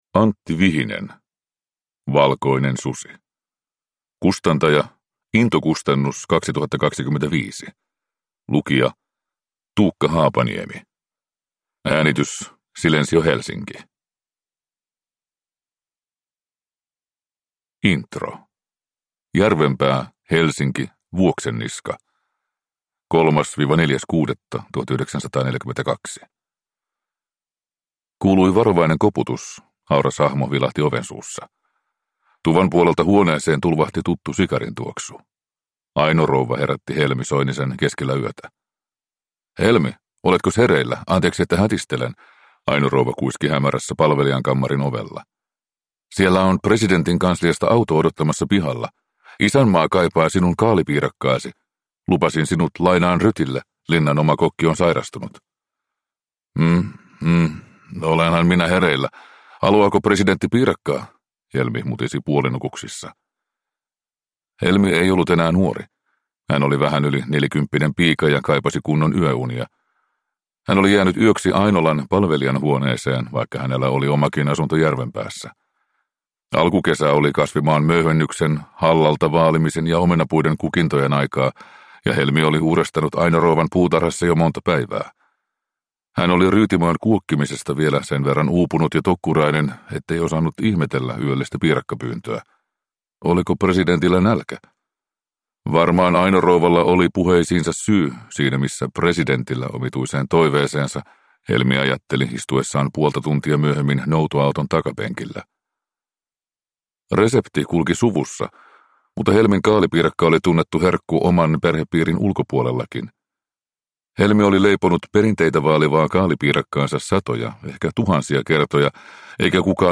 Valkoinen susi – Ljudbok